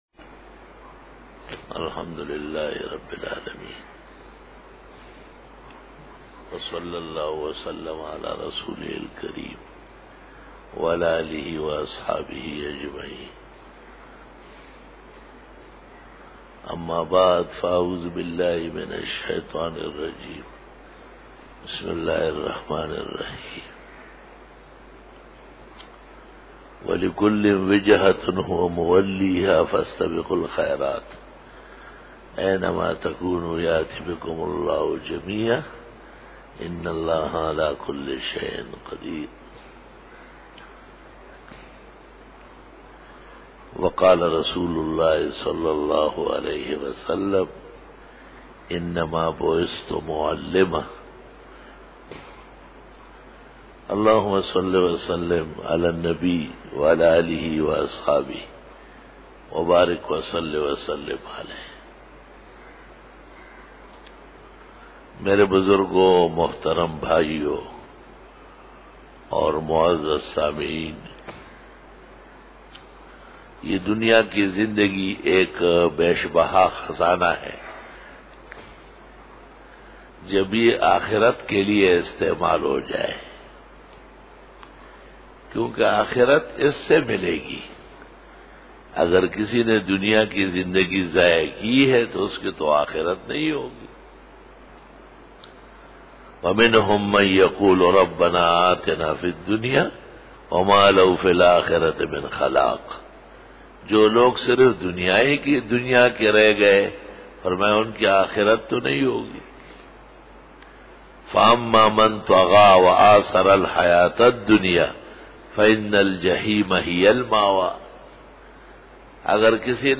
بیان جمعۃ المبارک
08:20 PM 249 Khitab-e-Jummah 2012 --